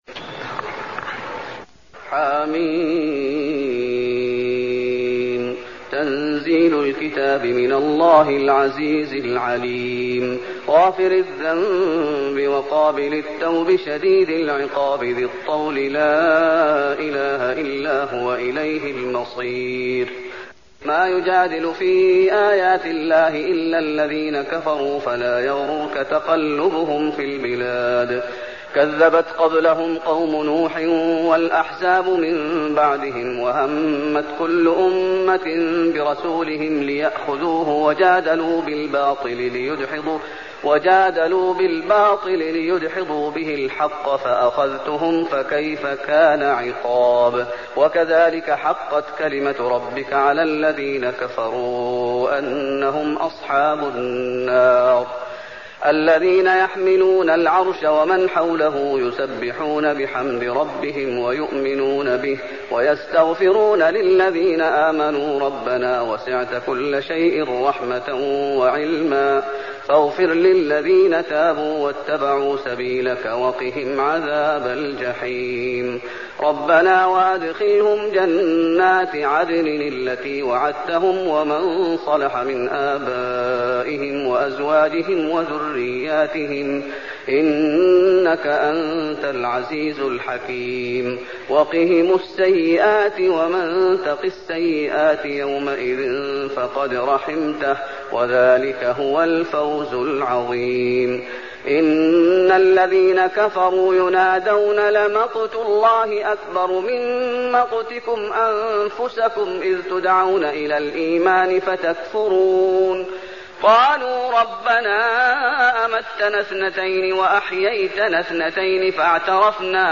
المكان: المسجد النبوي غافر